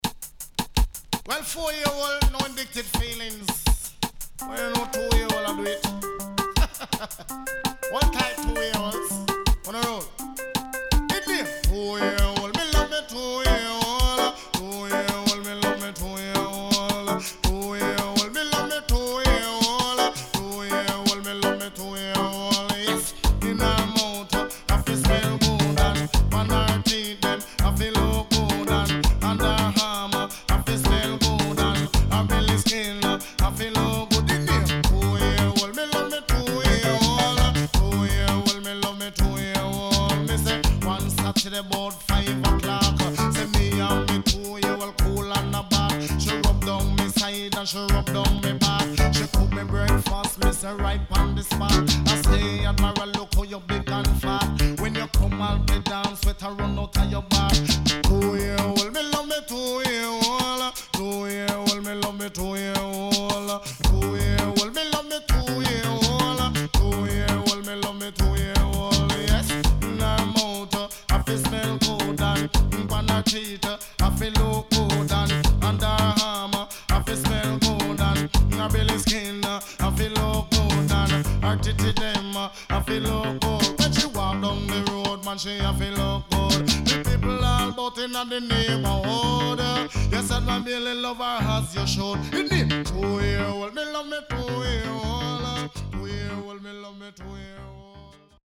HOME > DISCO45 [DANCEHALL]  >  FOUNDATION RIDDIM
SIDE A:序盤少しノイズ入りますが良好です。